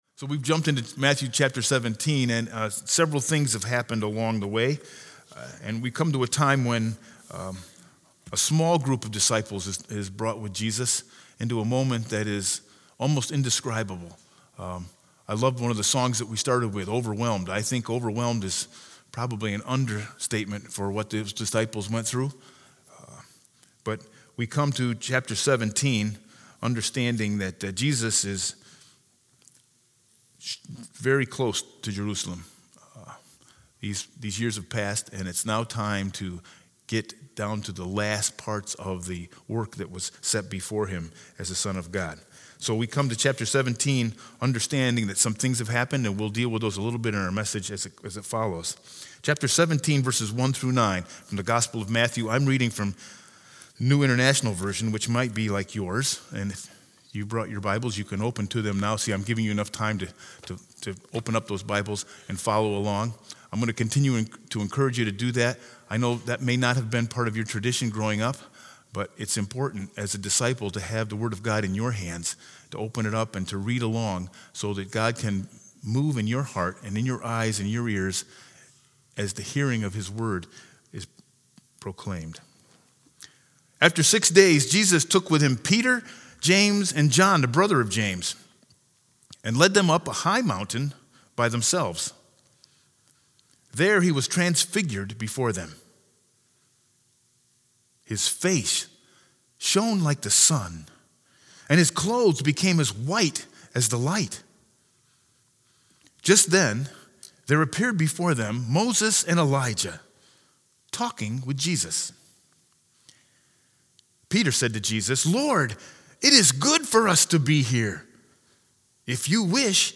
Sermon 2-23-20 with scripture lesson Matthew 17_1-9